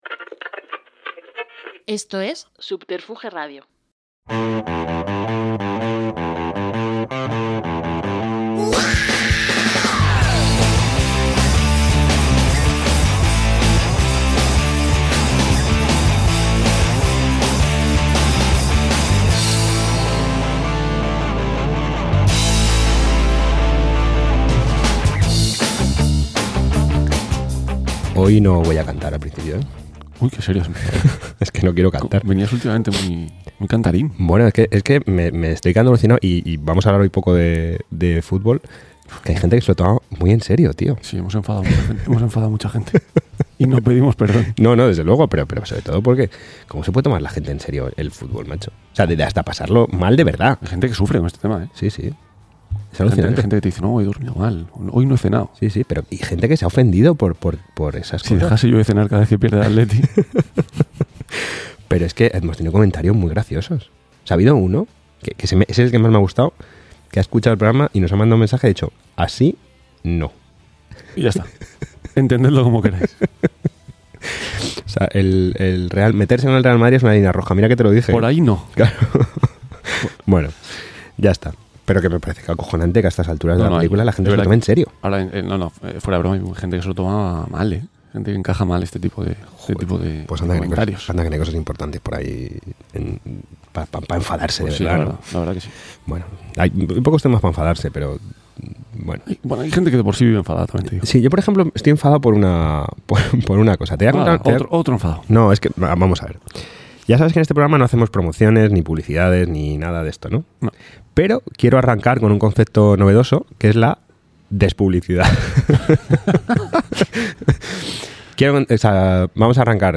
Si quieres conocerme mejor y saber, por ejemplo, de dónde sale el nombre del blog, escucha esta amena entrevista que me hicieron en el podcast gastronómico Todo para Compartir: